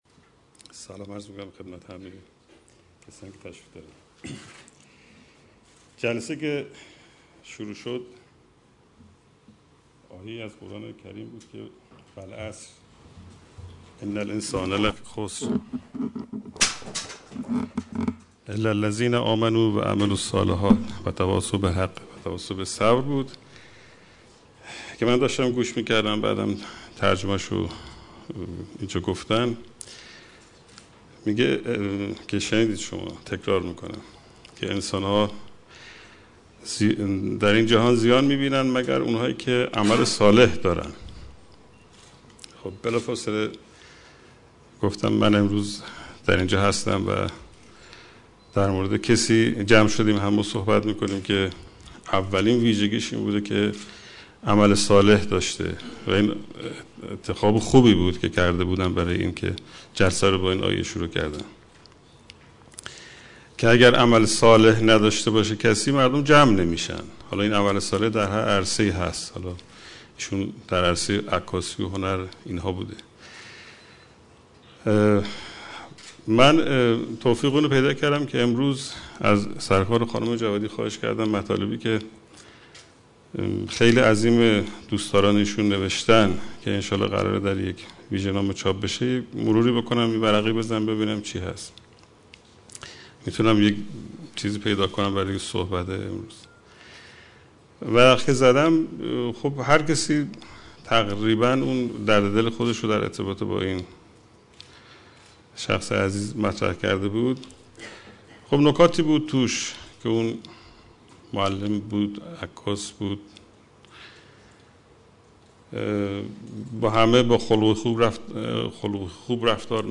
سخنان
در مراسم یادبود بهمن جلالی